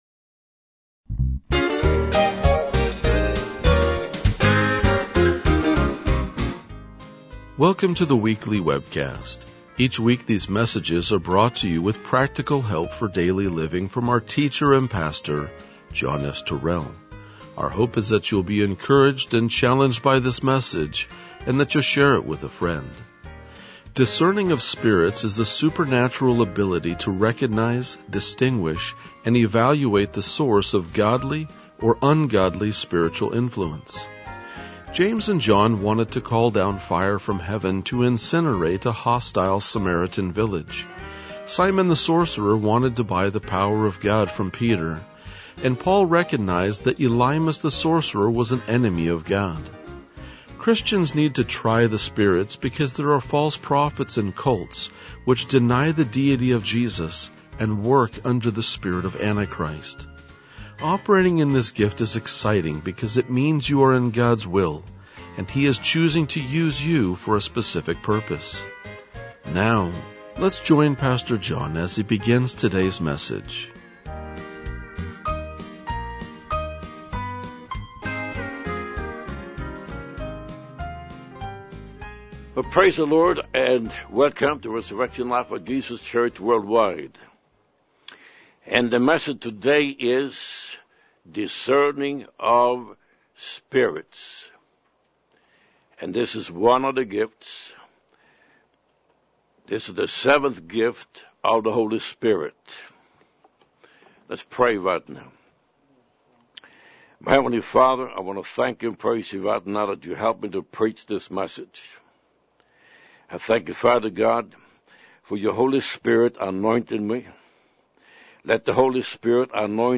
RLJ-2028-Sermon.mp3